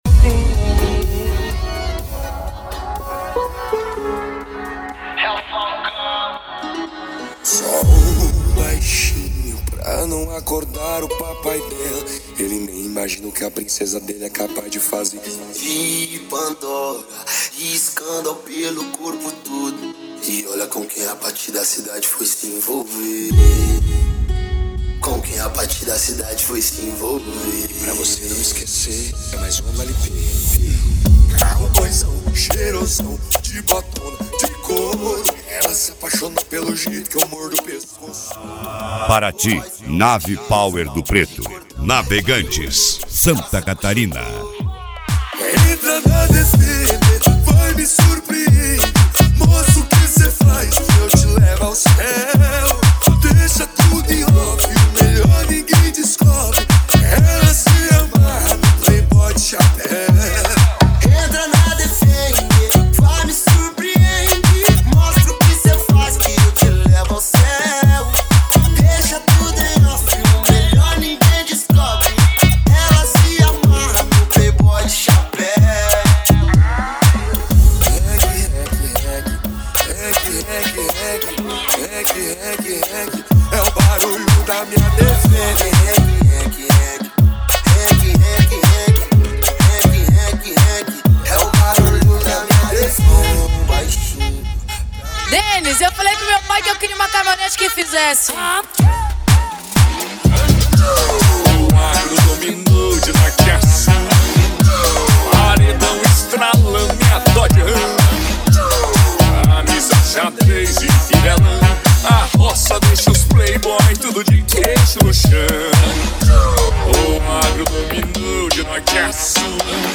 Mega Funk
SERTANEJO